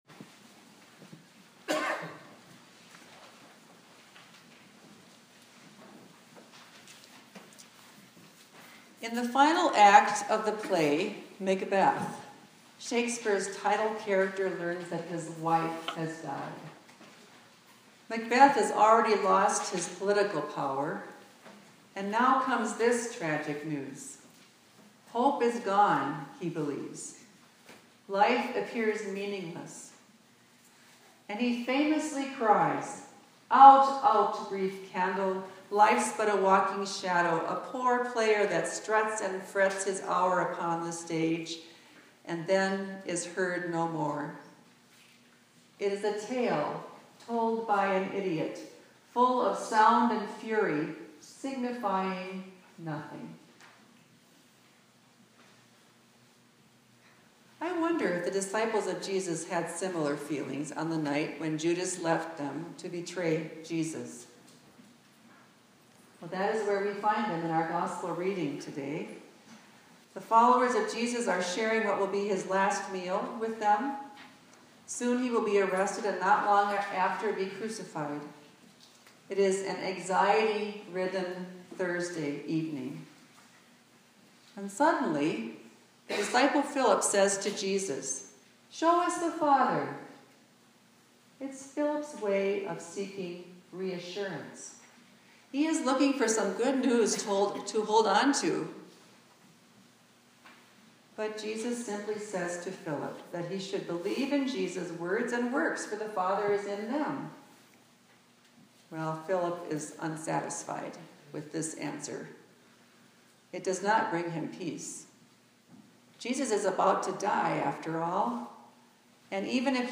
Sunday Worship – May 15, 2016 – Pentecost C